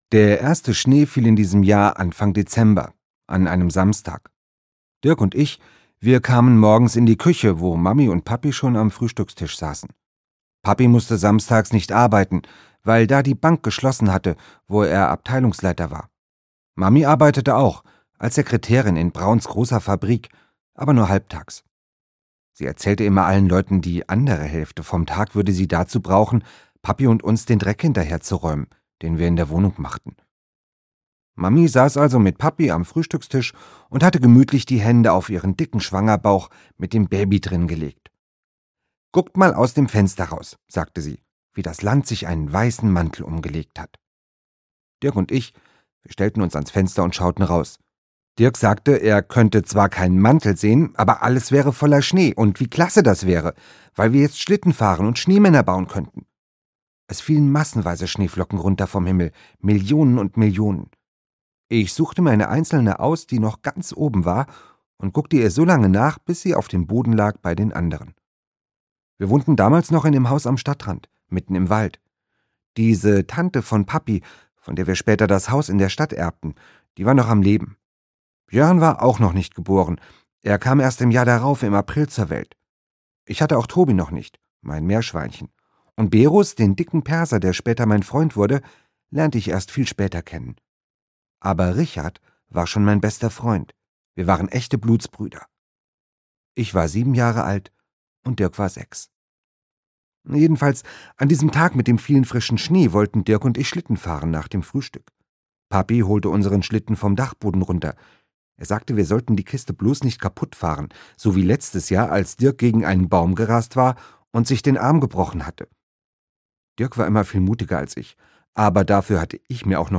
Dirk und ich (Jubiläumsausgabe) - Andreas Steinhöfel - Hörbuch